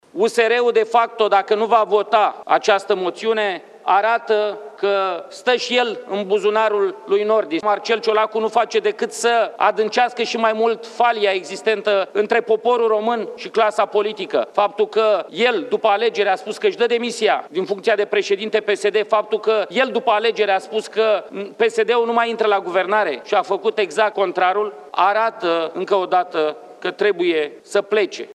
George Simion, președintele AUR: „Marcel Ciolacu nu face decât să adâncească și mai mult falia dintre poporul român și clasa politică”